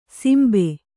♪ simbe